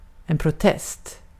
Ääntäminen
IPA : /əb.ˈdʒɛk.ʃən/ US : IPA : [əb.ˈdʒɛk.ʃən]